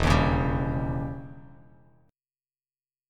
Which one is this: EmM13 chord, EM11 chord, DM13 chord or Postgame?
EmM13 chord